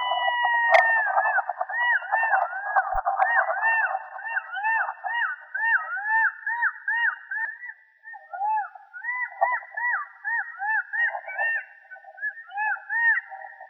Einzelruf